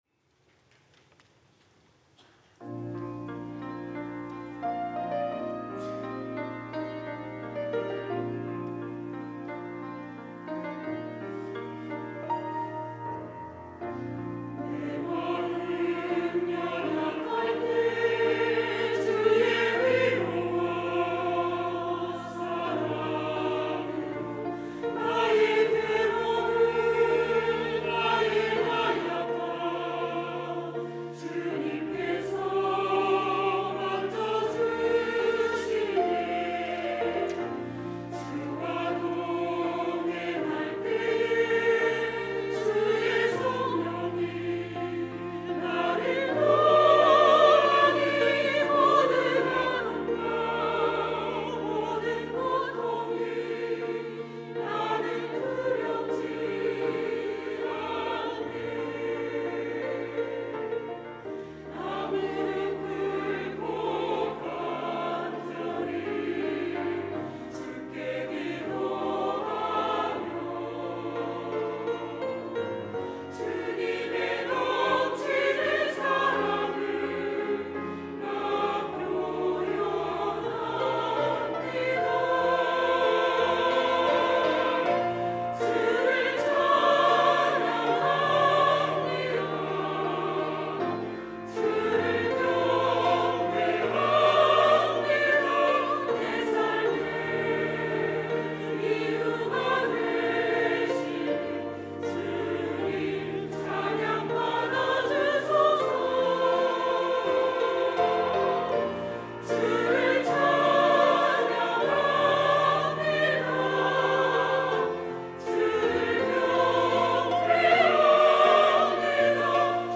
호산나(주일3부) - 찬양합니다
찬양대